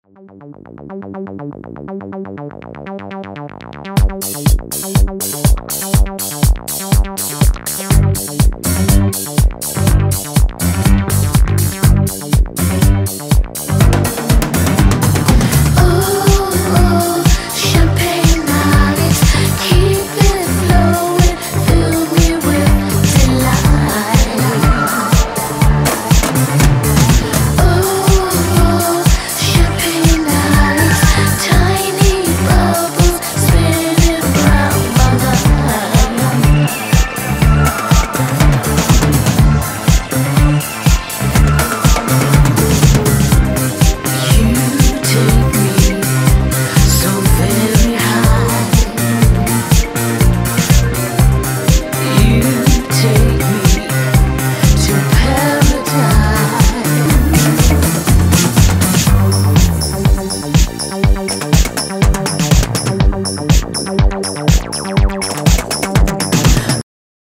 • Качество: 320, Stereo
диско
Italo Disco